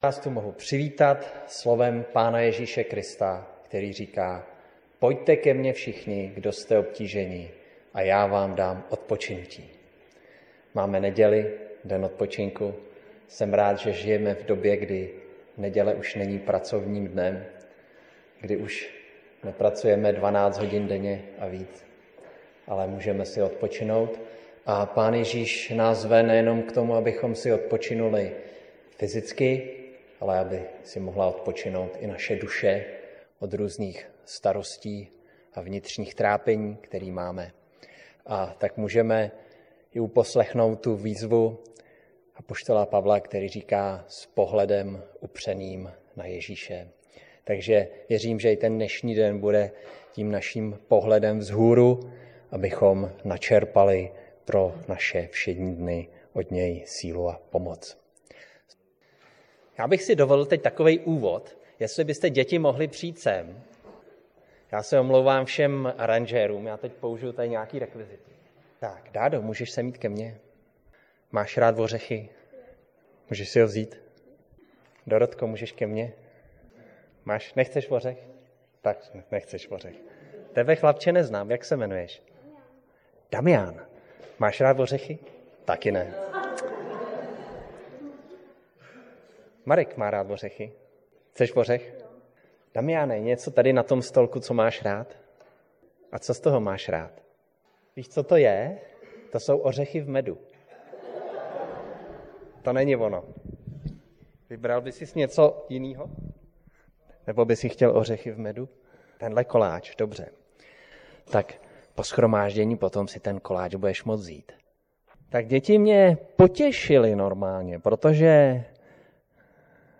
Díkůvzdání 2025 – část 1 Římanům 5:1–5 5 října 2025 Řečník: ---více řečníků--- Kategorie: Nedělní bohoslužby Husinec přehrát / pozastavit Váš prohlížeč nepodporuje přehrávání audio souborů. stáhnout mp3